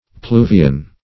pluvian - definition of pluvian - synonyms, pronunciation, spelling from Free Dictionary Search Result for " pluvian" : The Collaborative International Dictionary of English v.0.48: Pluvian \Plu"vi*an\, n. (Zool.) The crocodile bird.